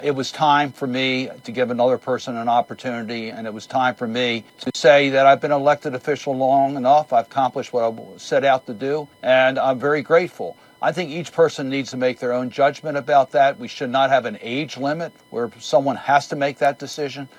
Outgoing Maryland Senator Ben Cardin appeared on ABC’s This Week with Jonathan Karl, talking about his role on the U.S. Senate Foreign Relations Committee and his time in elected office.